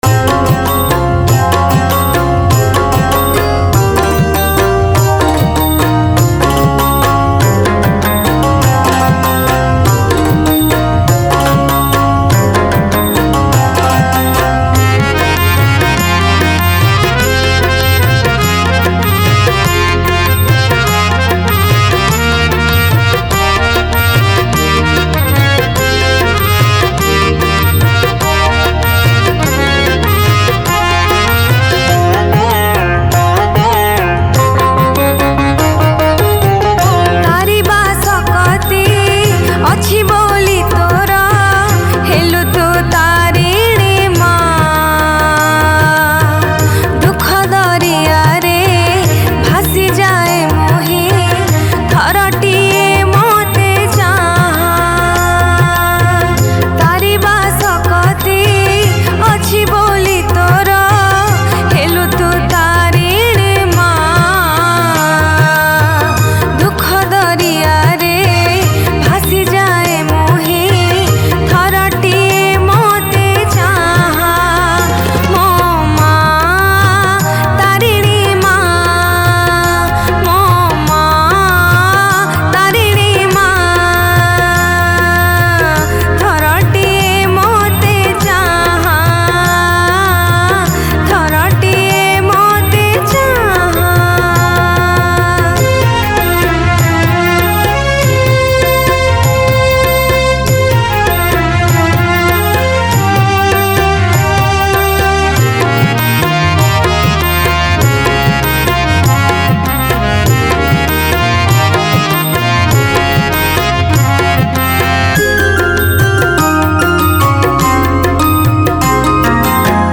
Category: New Odia Bhakti Songs 2022